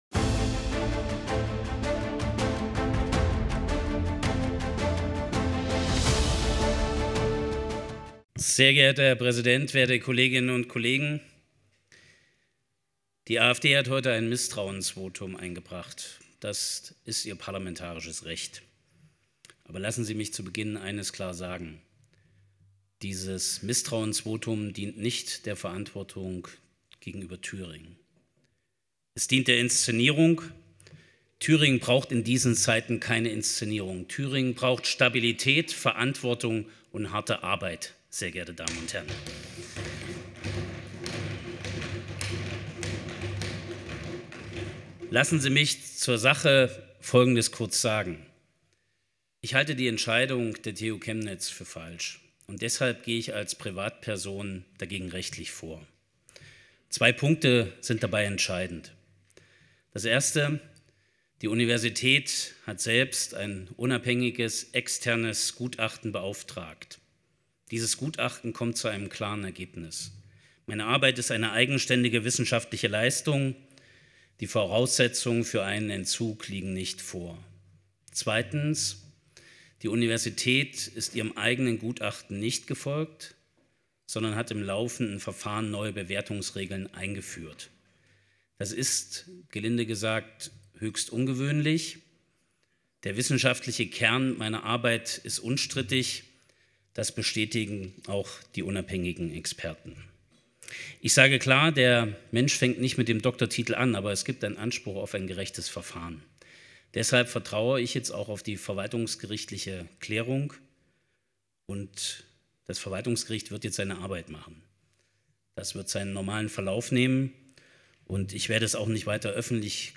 Der Bericht aus Erfurt dokumentiert an dieser Stelle die Rede von Mario Voigt (CDU) in der Landtagsdiskussion vor der Abstimmung.